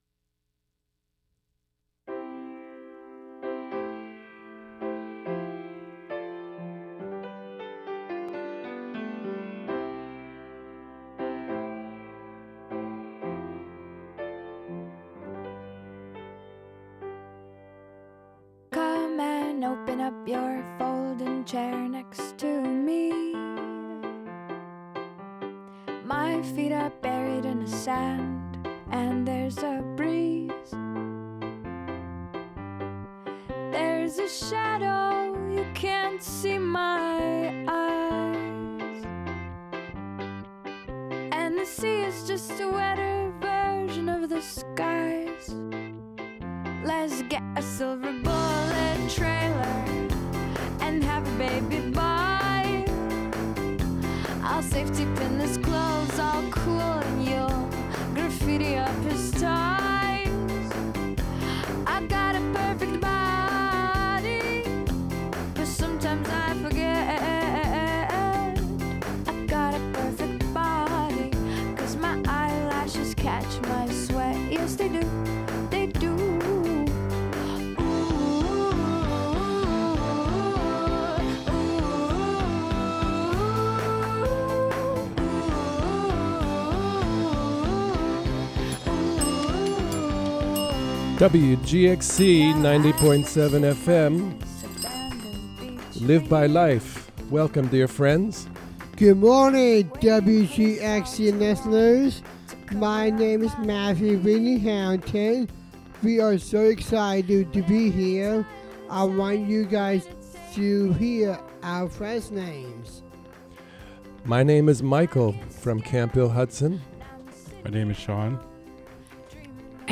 Members of the Camphill Hudson Radio Group from Camphill Hudson make their own radio.